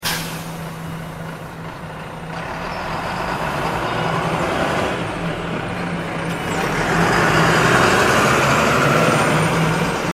bus.mp3